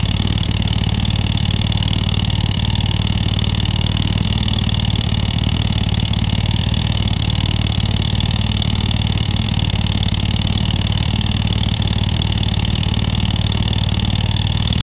2 strokes engine 50cc at constant 1000, 2000, 3000, 4000, 5000, 6000, 7000 rpm with no increasing distance or volume.
2s seconds for each rpm level.
2-strokes-engine-50cc-at-igy63xlh.wav